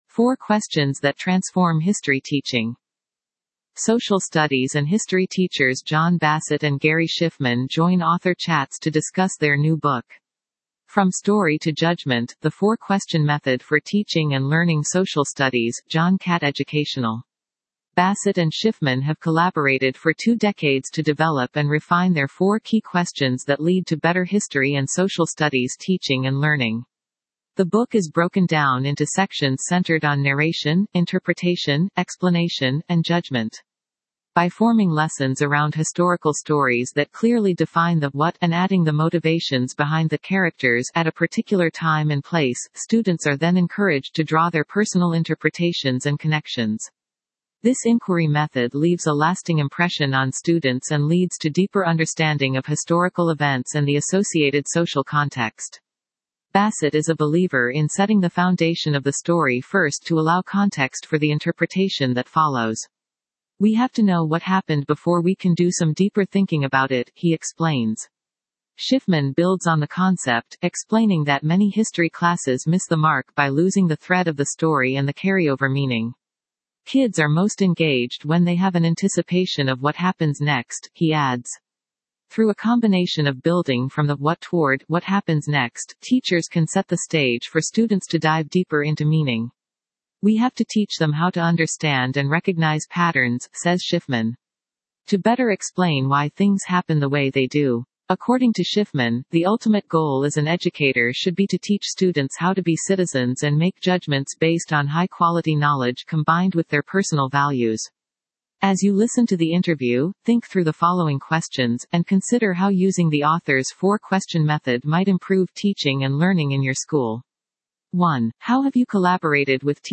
As you listen to the interview, think through the following questions, and consider how using the authors’ Four Question Method might improve teaching and learning in your school: